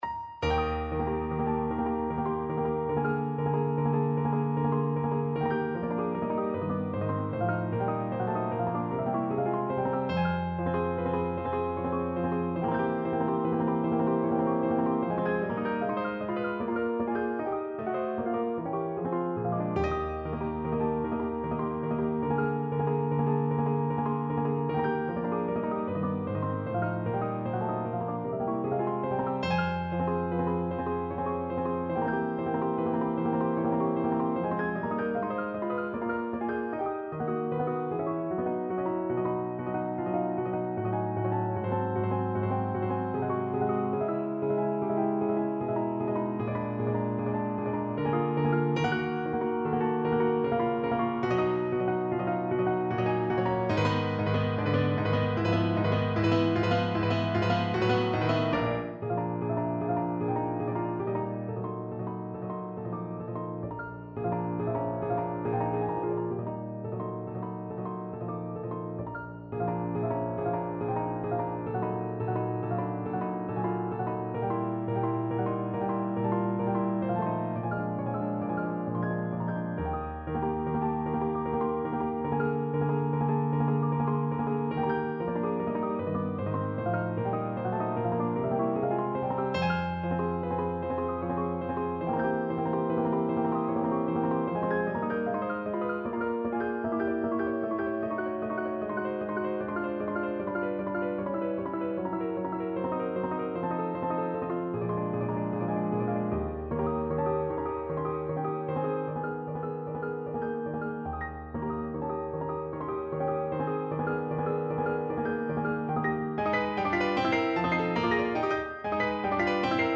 No parts available for this pieces as it is for solo piano.
Allegretto = 76
3/4 (View more 3/4 Music)
Piano  (View more Advanced Piano Music)
Classical (View more Classical Piano Music)